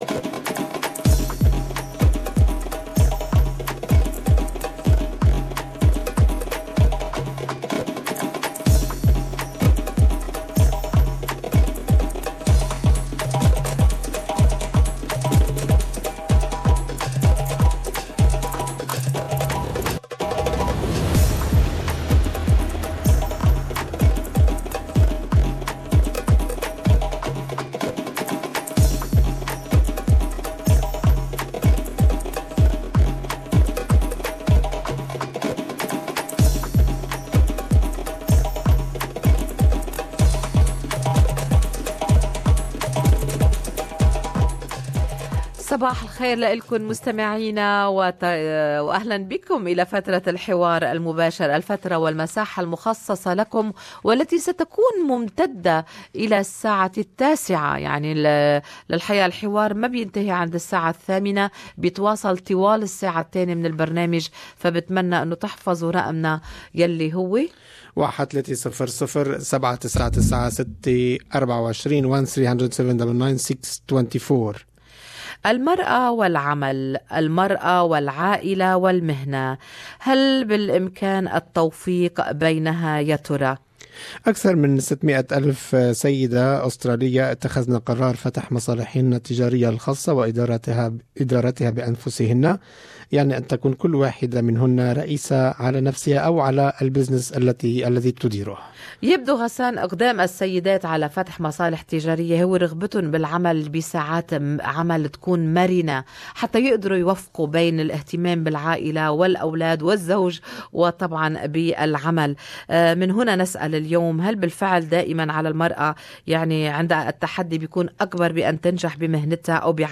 طرحنا هذا لموضوع للنقاش في الحوار المباشر ، اراء المستمعين